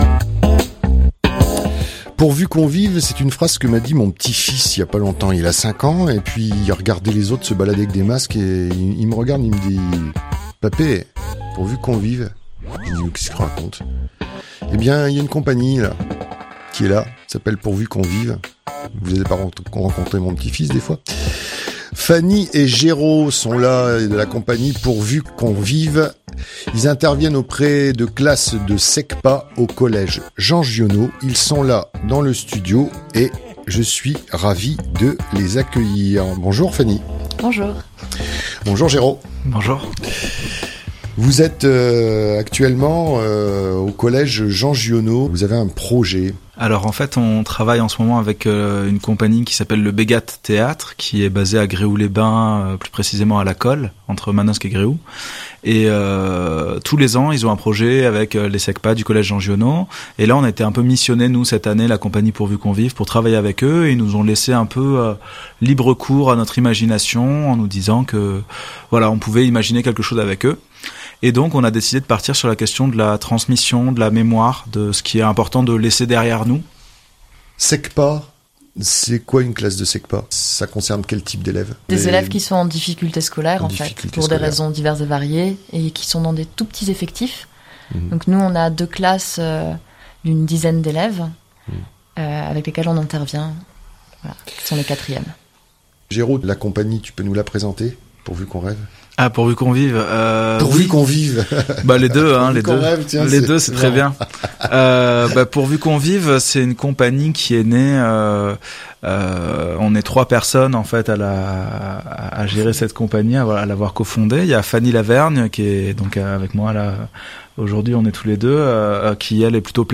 Entrevue avec La compagnie " pourvu qu'on vive "